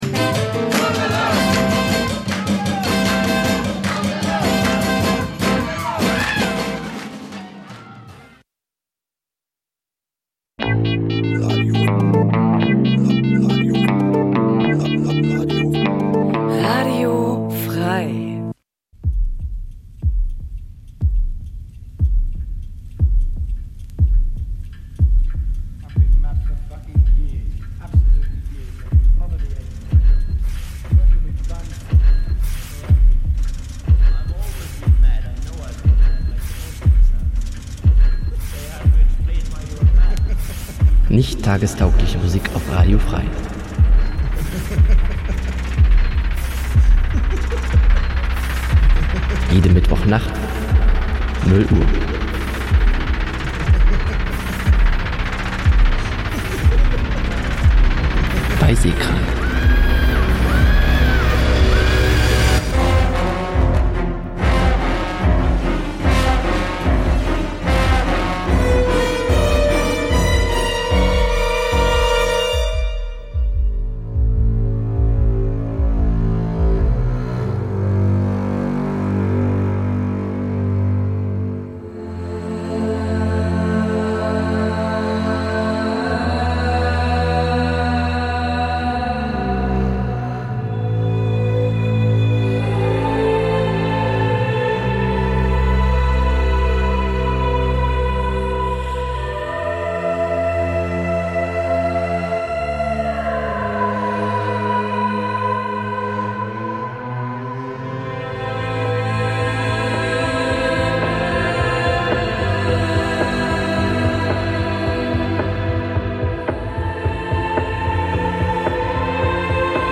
SEHKRANK pr�sentiert Musik auch jenseits dieser alternativen H�rgewohnheiten, mal als Album, mal als Mix.